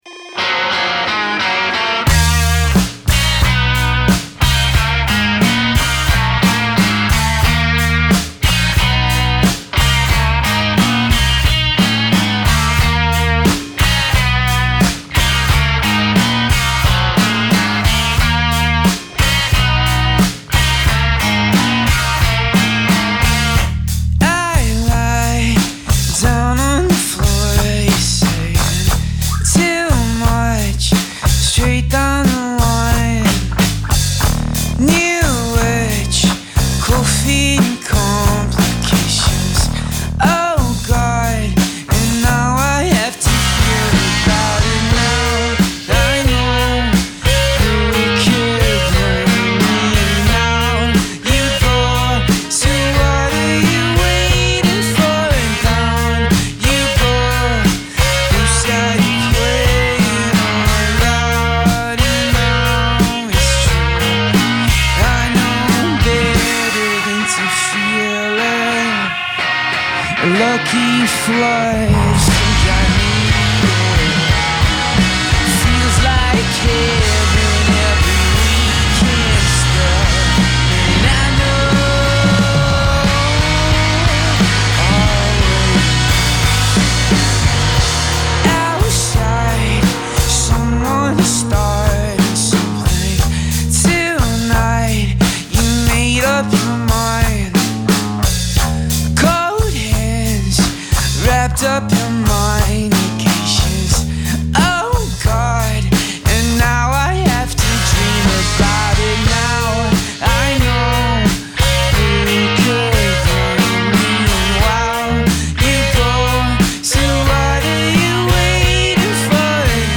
Salford studio
recorded on October 15, 2024 at Salford studios.